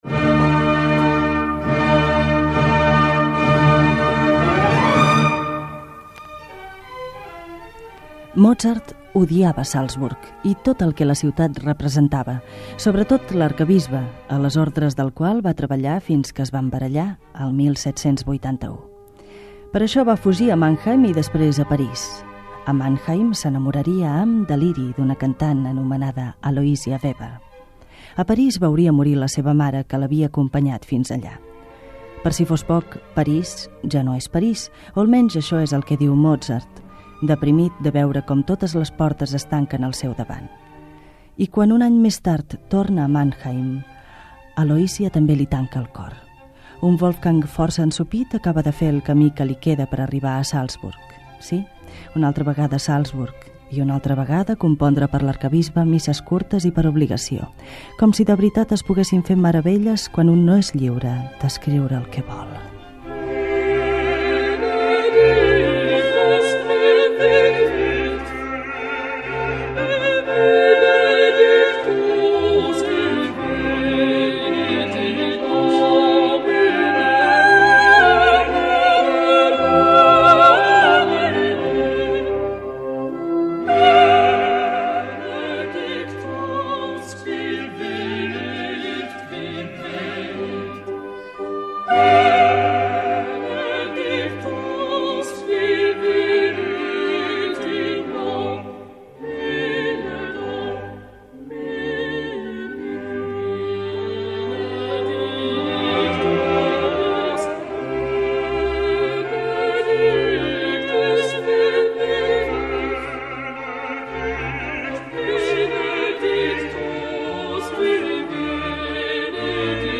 "200 anys de la mort de Mozart" Dades biogràfiques del compositor Wolfgang Amadeus Mozart i alguns fragments dramatitzats de la seva vida
Musical